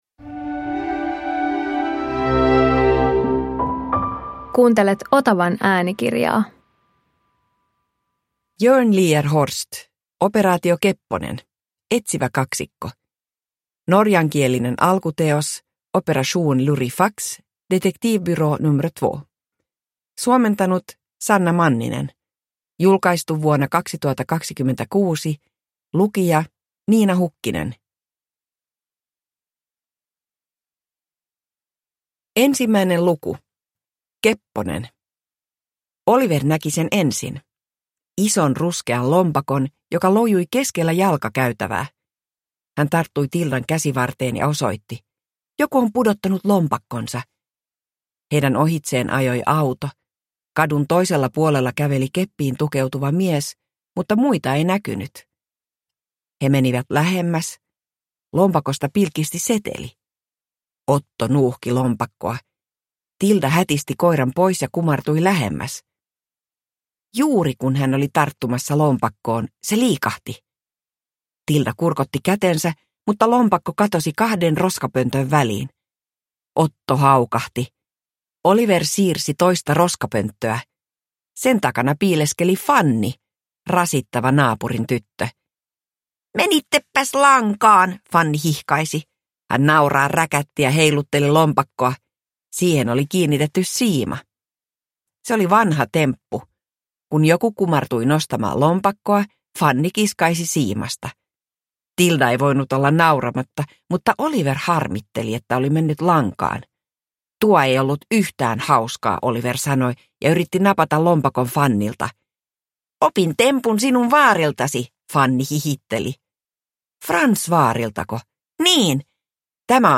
Operaatio Kepponen – Ljudbok